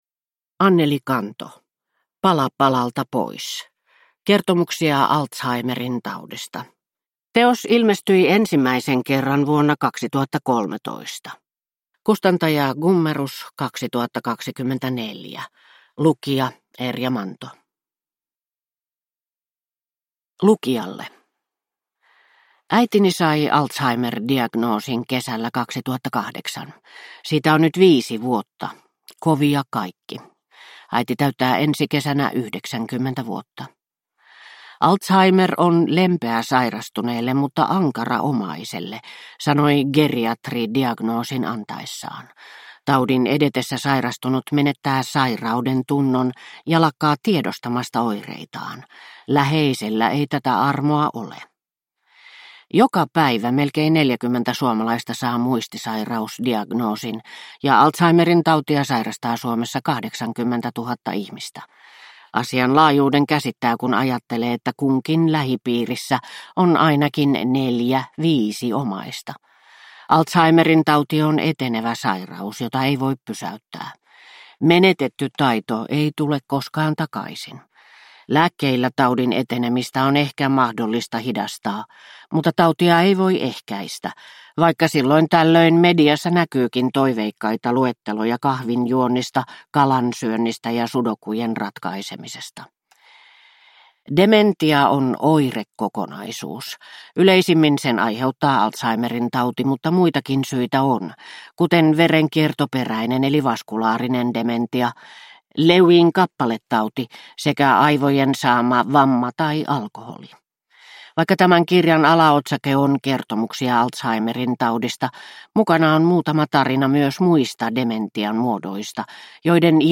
Pala palalta pois – Ljudbok